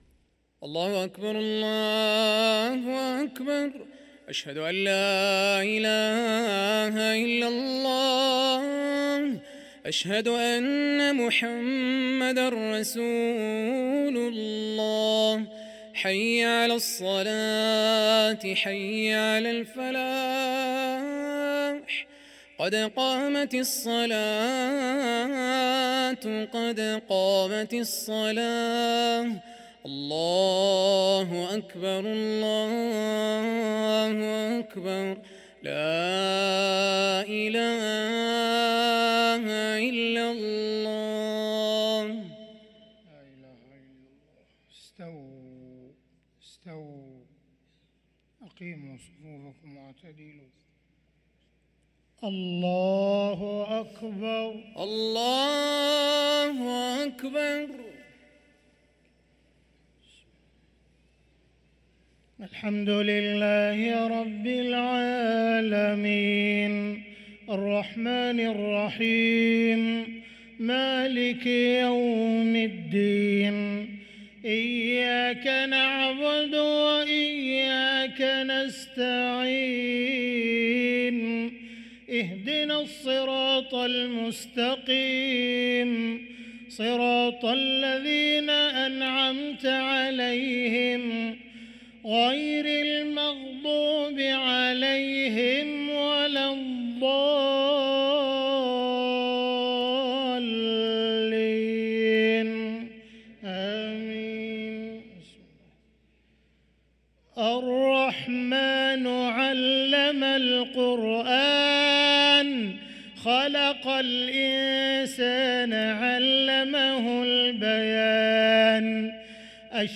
صلاة العشاء للقارئ عبدالرحمن السديس 22 ربيع الآخر 1445 هـ
تِلَاوَات الْحَرَمَيْن .